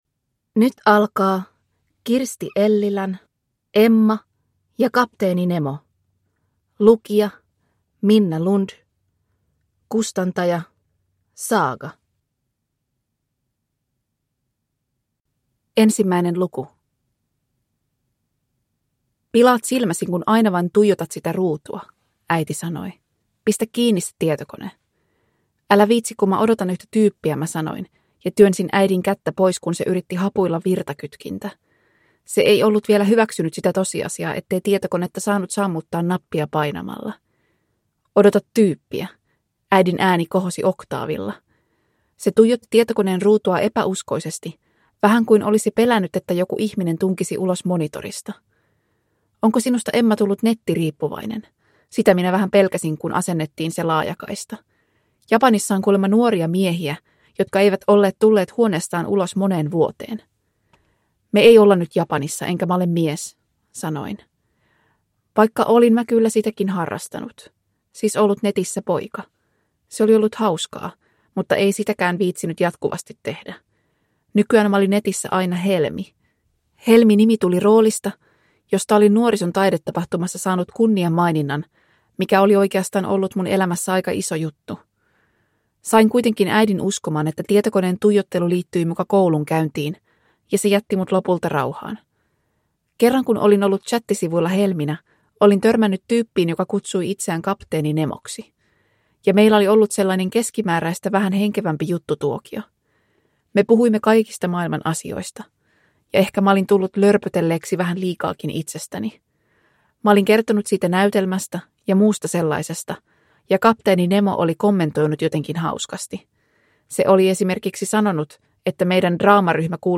Emma ja kapteeni Nemo (ljudbok) av Kirsti Ellilä